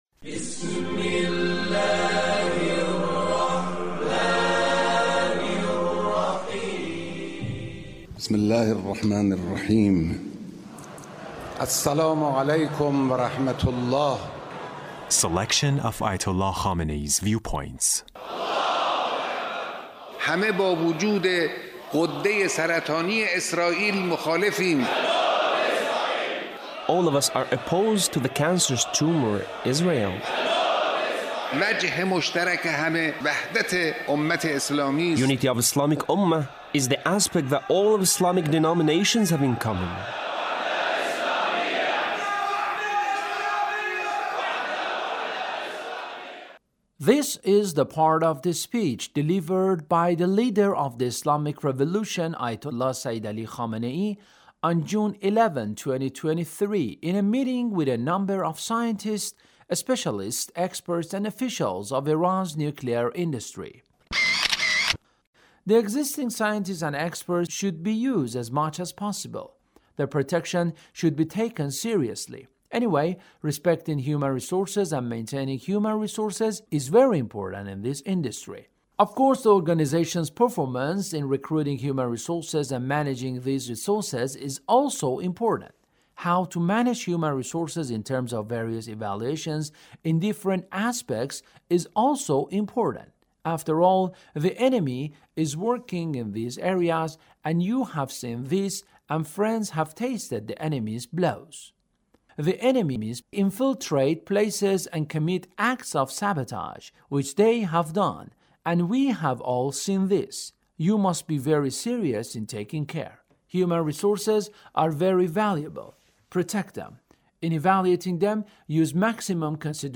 Leader's Speech in a meeting with a number of scientists,and officials of Iran’s nuclear industry